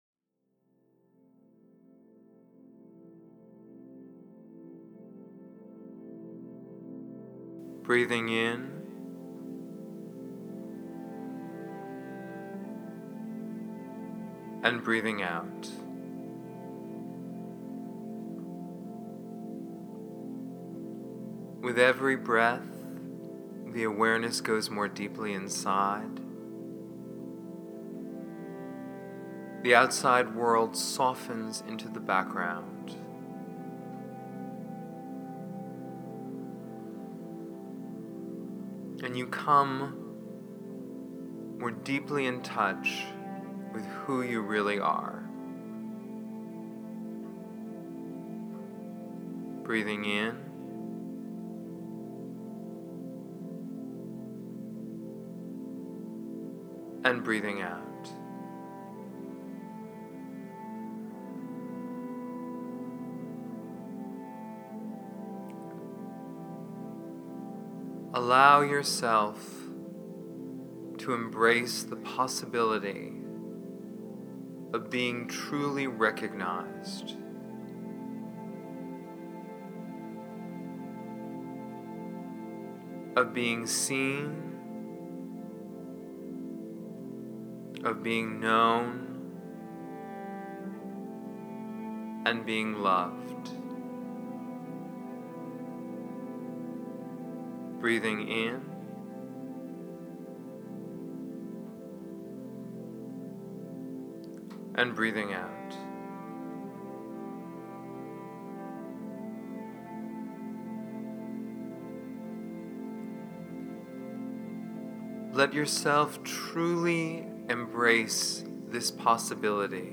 Recognition-Meditation.mp3